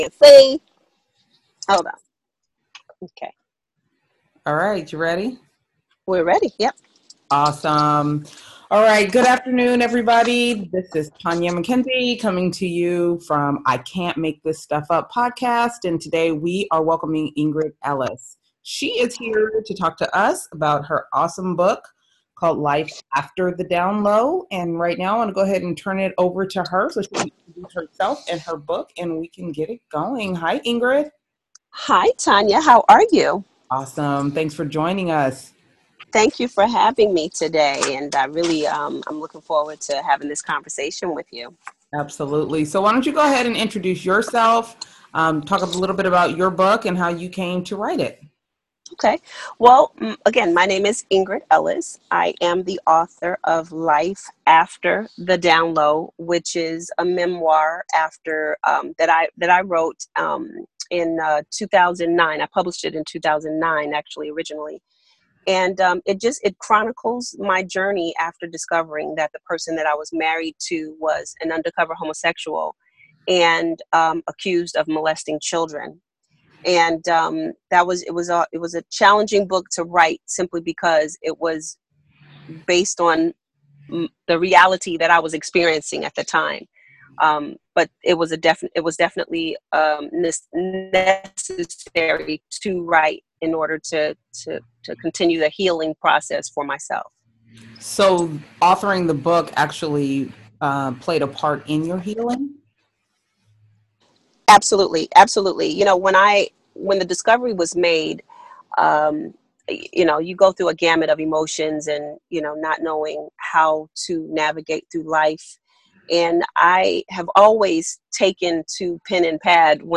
The passage that she reads, about the moments walking down the aisle and the tears that he shed, later acknowledging that those tears were his farewell to the secret life of pedophilia and desire to be with men instead of this one woman came to life.